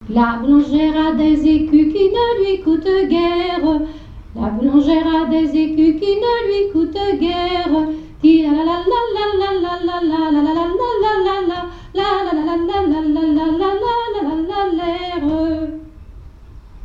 Mémoires et Patrimoines vivants - RaddO est une base de données d'archives iconographiques et sonores.
Rondes enfantines à baisers ou mariages
airs de danses et chansons traditionnelles
Pièce musicale inédite